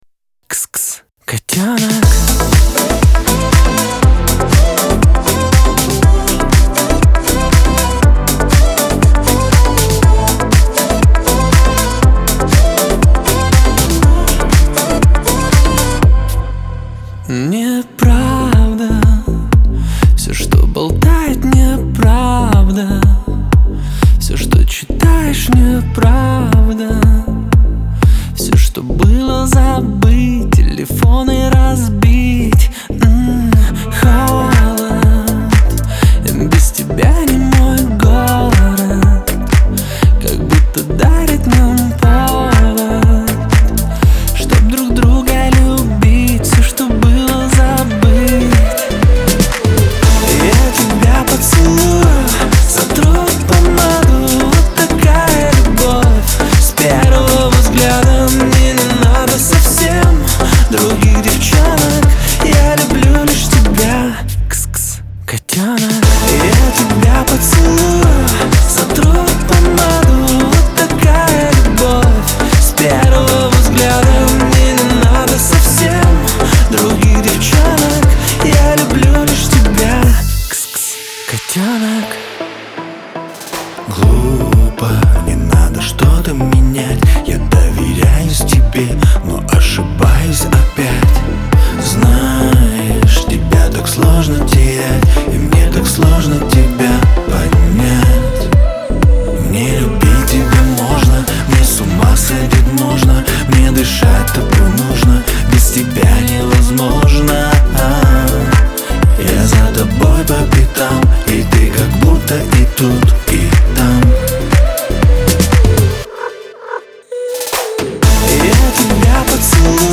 ХАУС-РЭП